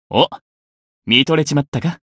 觉醒语音 哦，看入迷了吗 お、見惚れちまったか 媒体文件:missionchara_voice_537.mp3